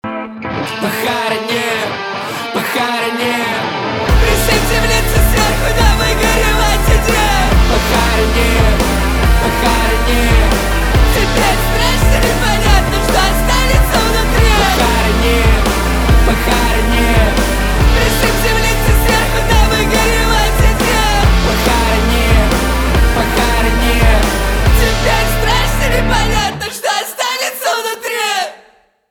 альтернатива
гитара , барабаны , грустные , жесткие , качающие